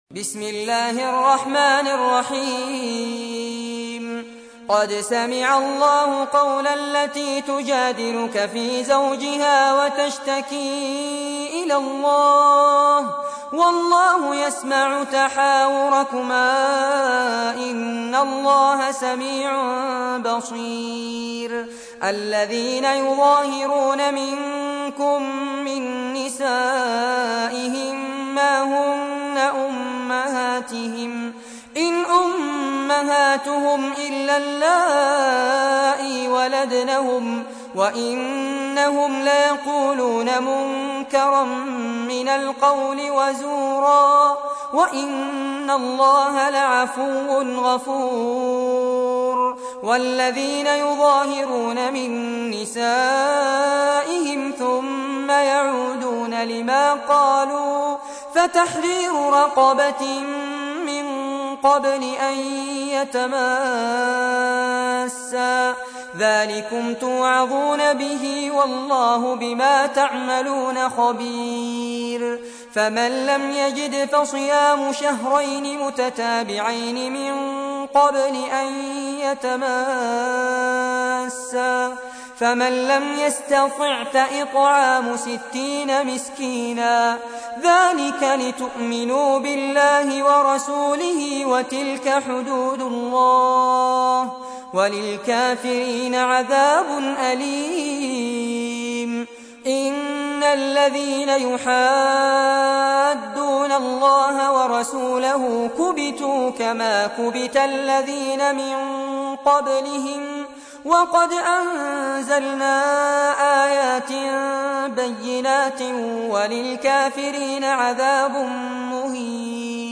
تحميل : 58. سورة المجادلة / القارئ فارس عباد / القرآن الكريم / موقع يا حسين